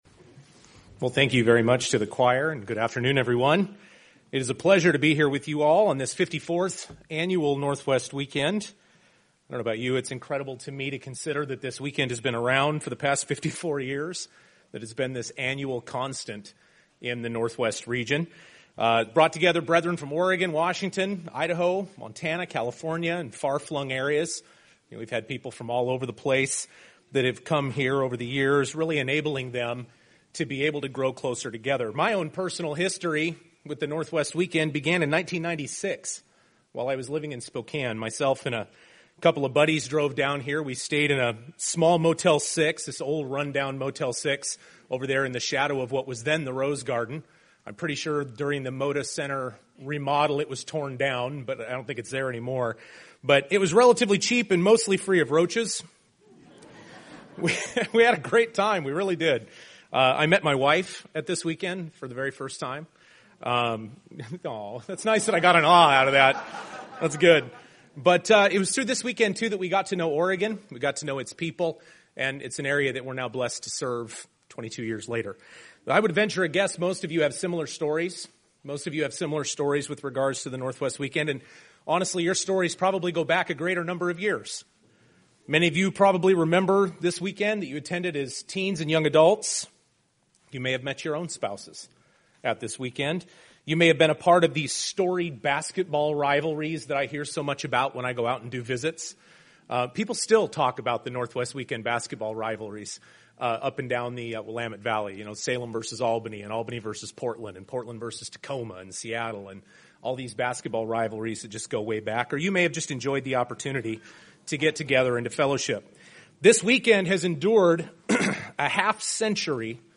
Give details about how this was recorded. What does God expect of us? This message was presented during the Northwest Family Weekend.